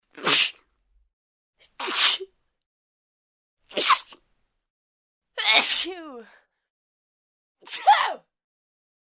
Звуки чихания
Женщина чихает nДама чихает nЧихающая женщина nЖенский чих nЧих дамы